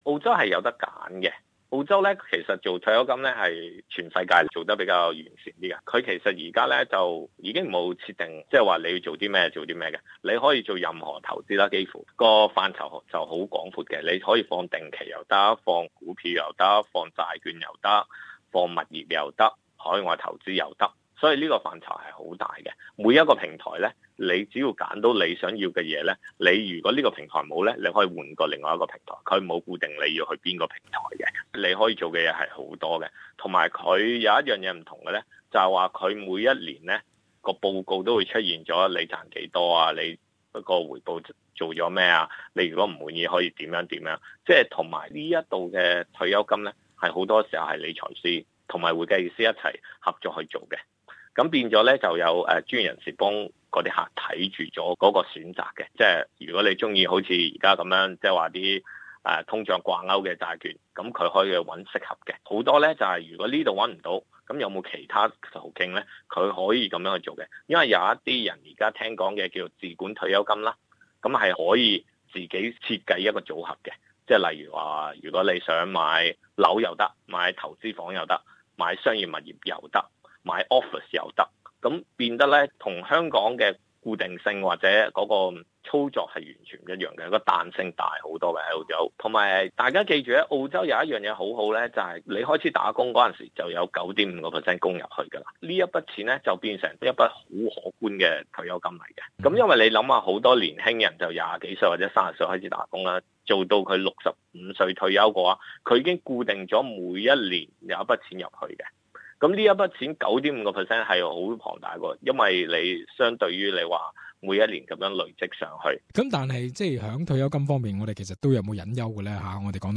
SBS Cantonese